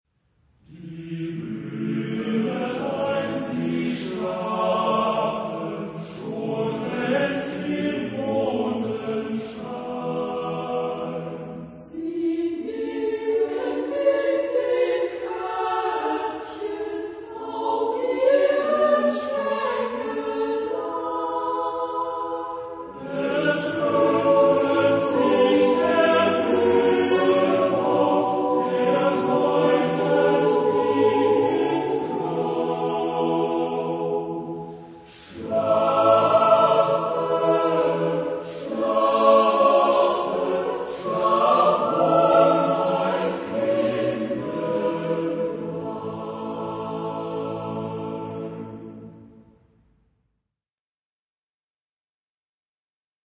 Genre-Stil-Form: Liedsatz ; Volkslied ; geistlich
Chorgattung: SSAATTBB  (8 gemischter Chor Stimmen )
Tonart(en): A-Dur